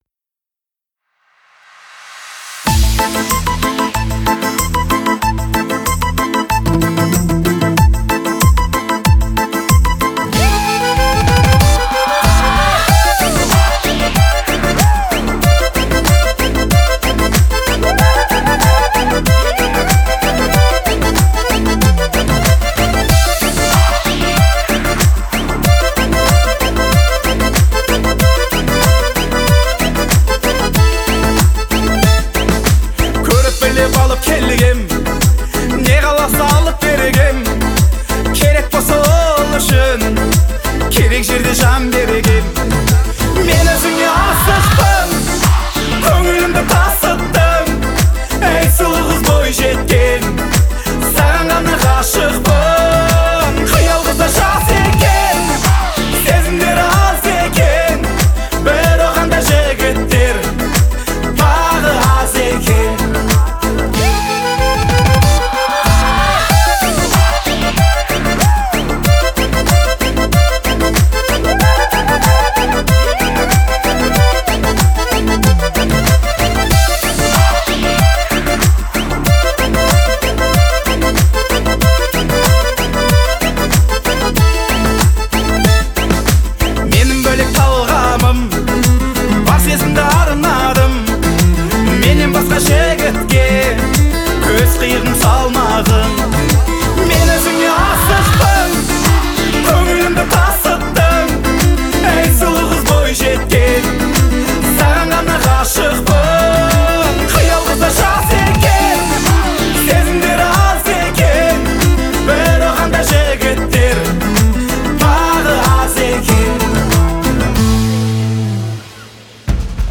нежная и мелодичная песня